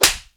SLAP   4.WAV